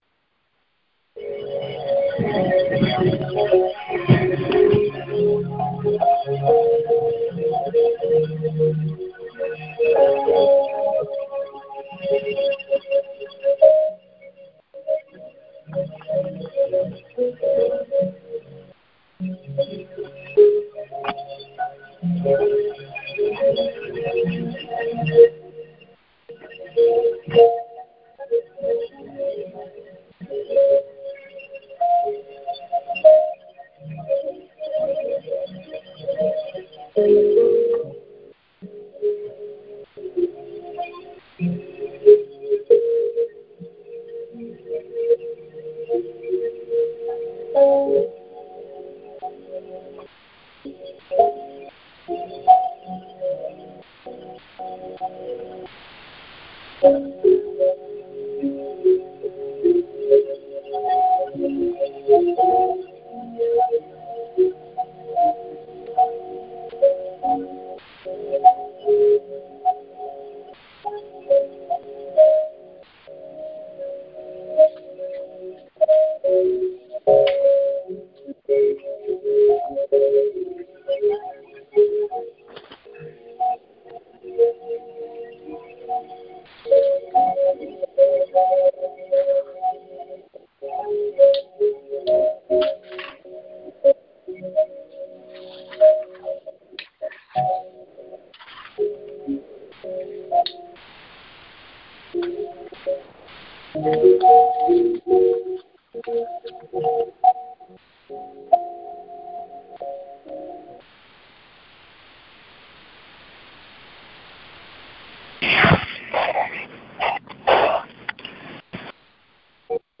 What a POWERFUL word, prayer and prophecy the Lord released this morning!